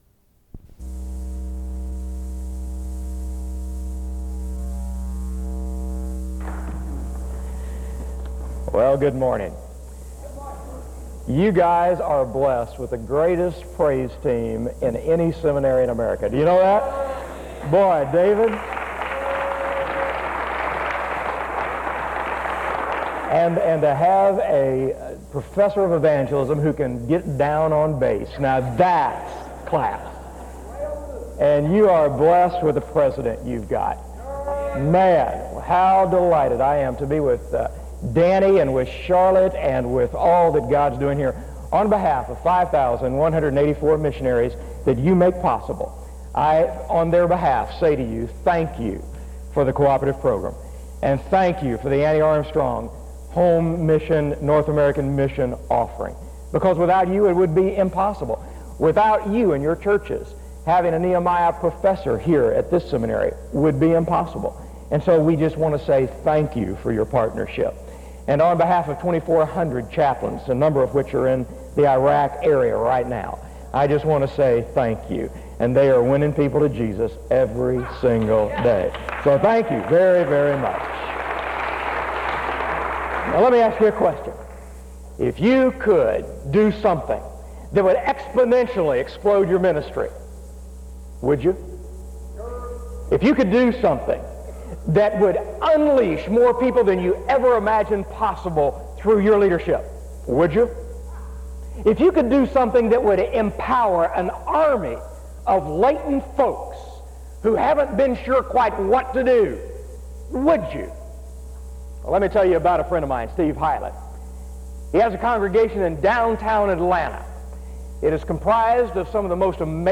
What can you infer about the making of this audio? Home SEBTS Chapel